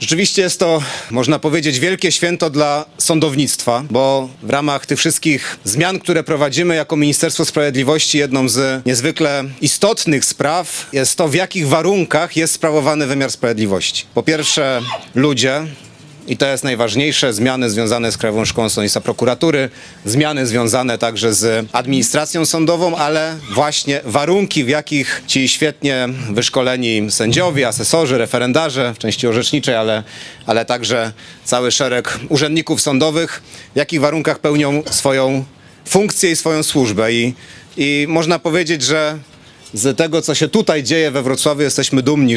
Przy ulicy Zielińskiego odbyło się wmurowanie aktu erekcyjnego pod budowę nowego gmachu Sądu Apelacyjnego we Wrocławiu.
„To wielkie święto dla sądownictwa”- powiedział wiceminister sprawiedliwości Michał Woś.